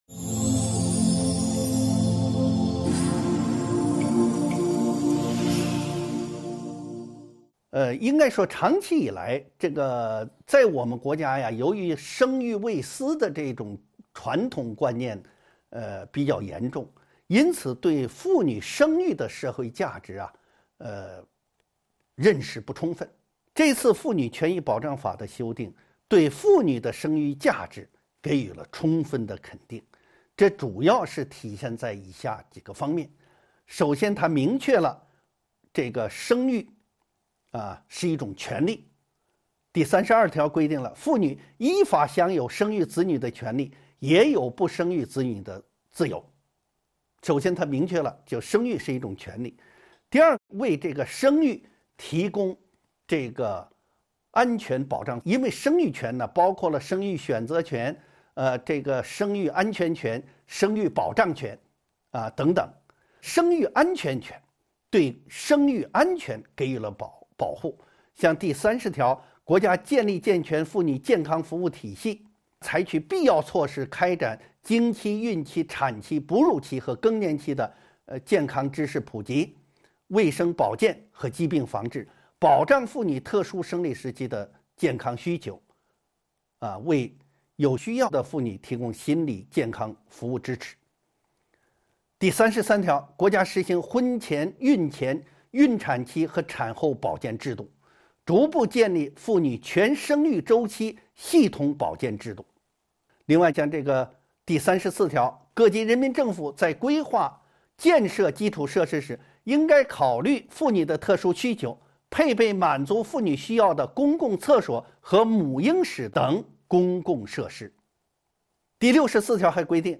音频微课：《中华人民共和国妇女权益保障法》17.妇女生育的社会价值及其保障